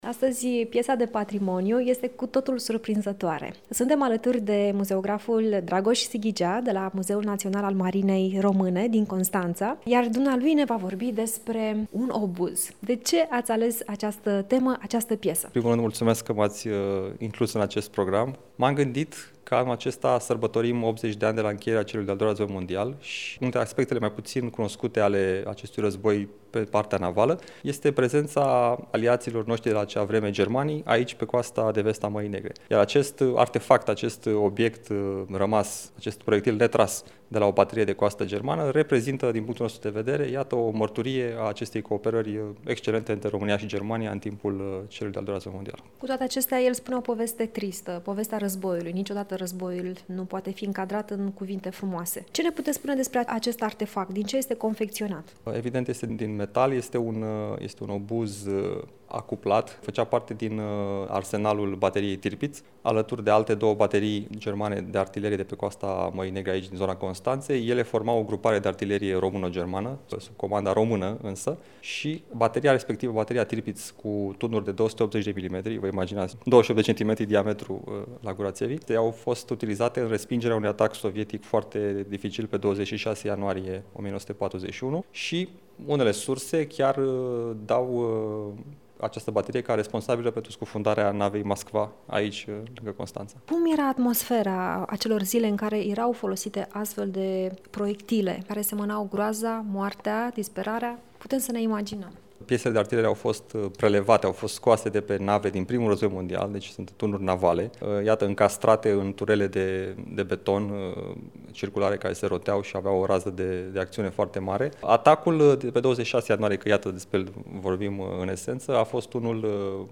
într-un dialog